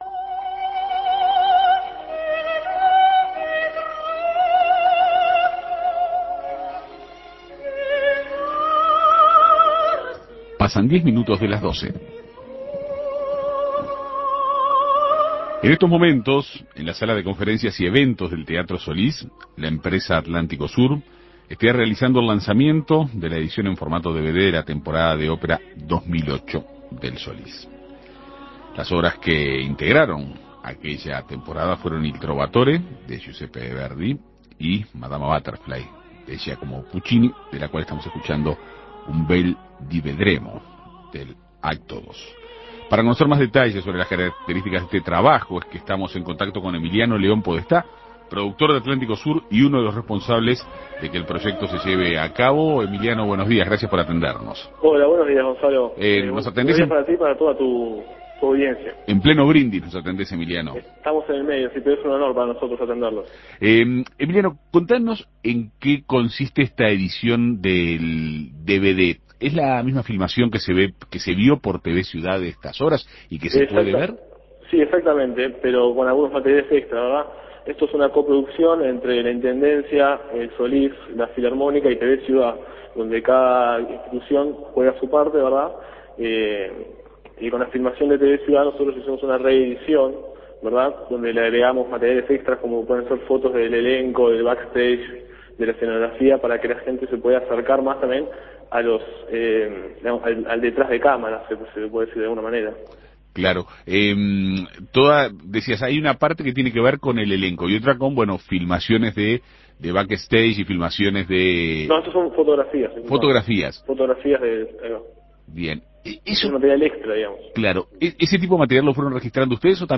Este martes en la Sala de Conferencias y Eventos del Teatro Solís, la empresa AtlánticoSur realizó el lanzamiento de la edición, en formato DVD, de la Temporada de Ópera 2008 de la institución. Las obras que la integran son "Il trovatore" de Giuseppe Verdi y "Madama Butterfly", de Giacomo Puccini. Para conocer más detalles sobre las características del espectáculo, En Perspectiva Segunda Mañana dialogó con